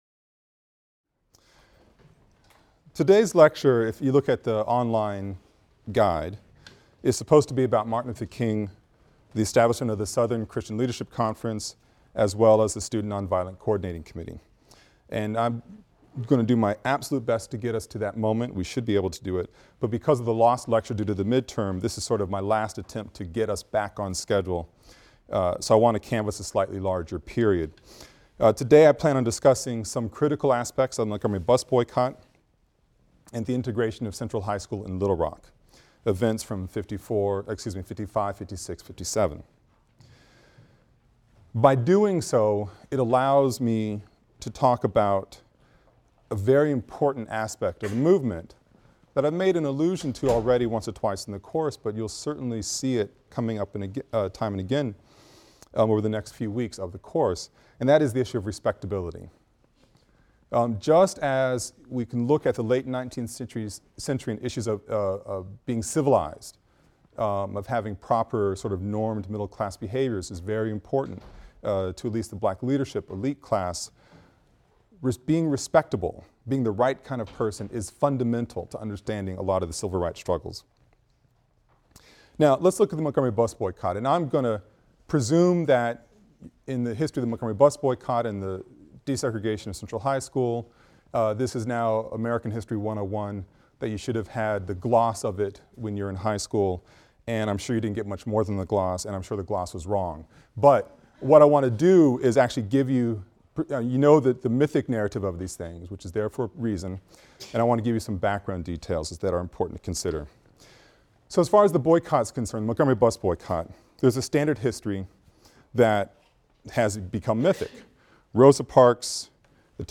AFAM 162 - Lecture 14 - From Sit-Ins to Civil Rights | Open Yale Courses